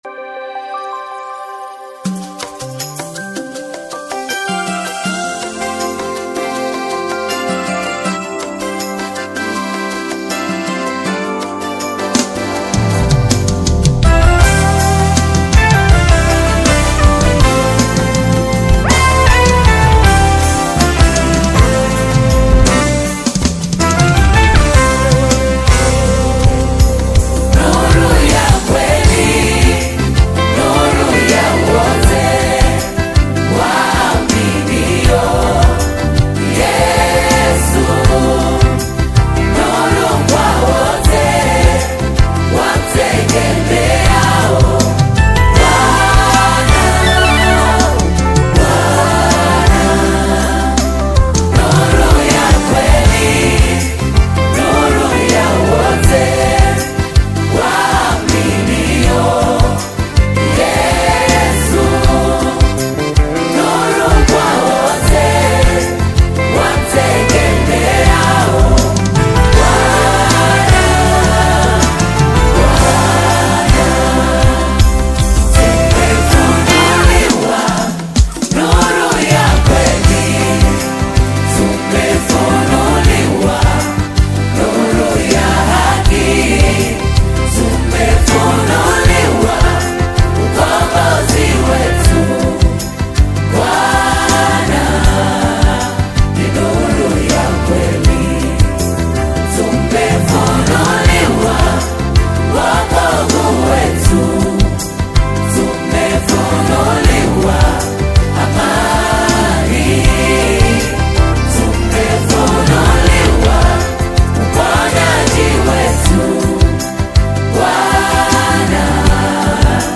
harmonically rich and rhythmically vibrant